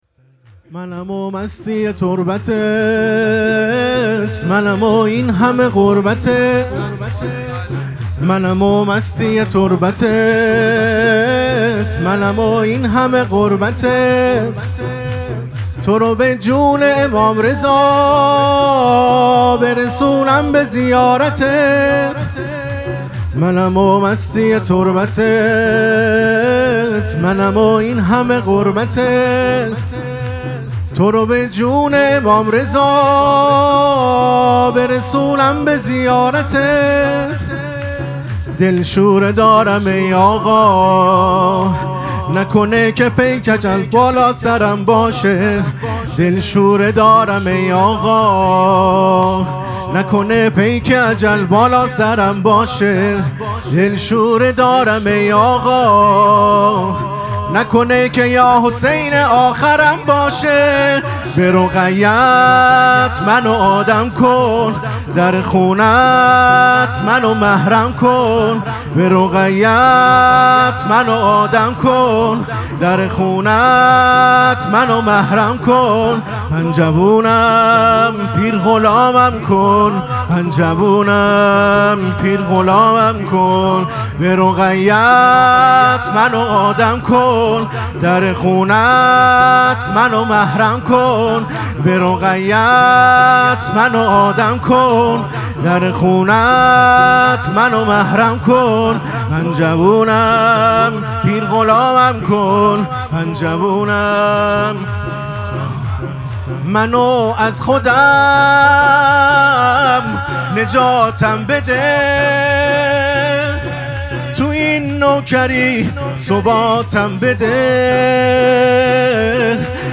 مراسم مناجات شب هشتم ماه مبارک رمضان
شور
مداح